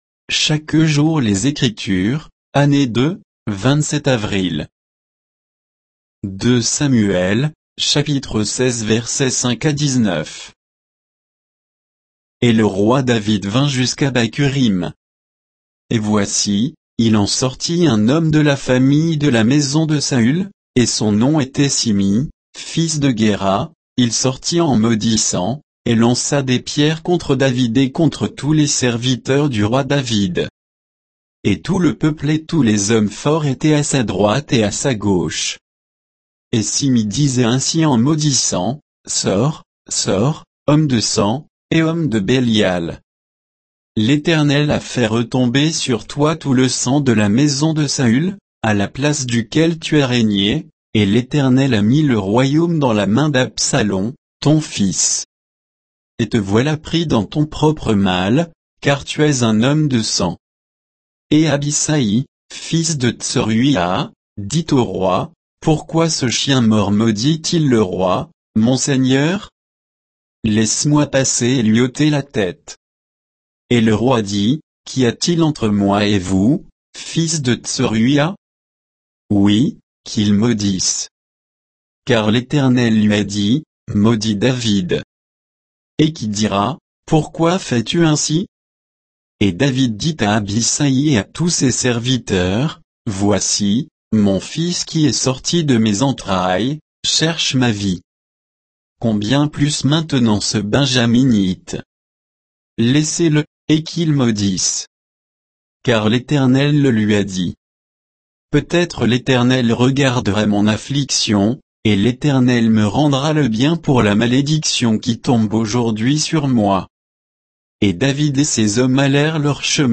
Méditation quoditienne de Chaque jour les Écritures sur 2 Samuel 16